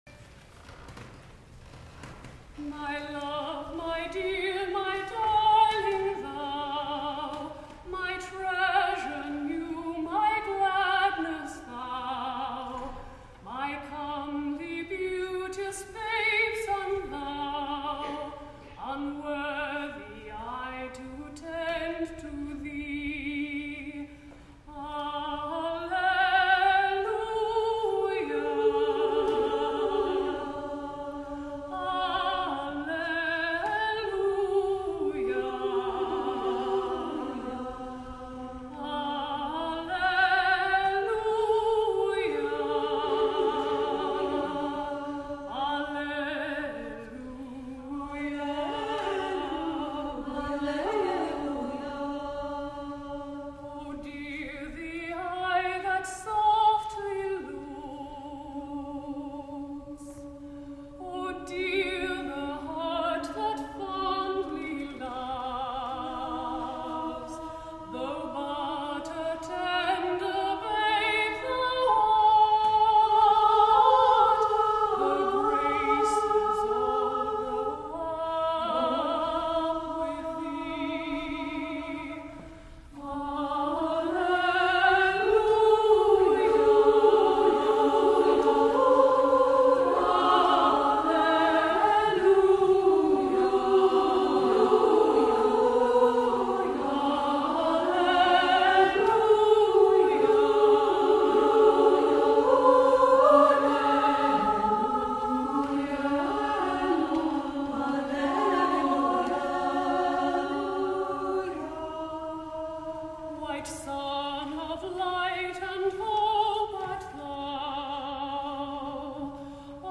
for SSA Chorus (1996)